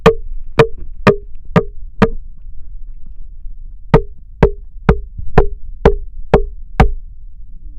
두드리는01.ogg